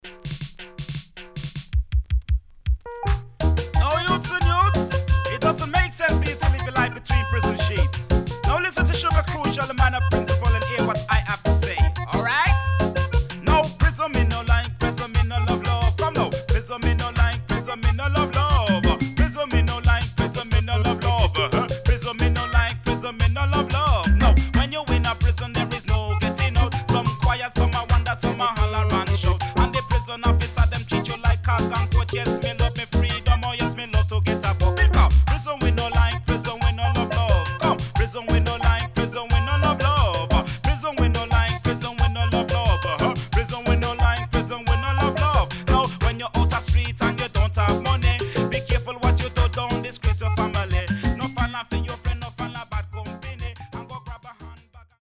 HOME > Back Order [DANCEHALL DISCO45]
SIDE A:少しチリノイズ入りますが良好です。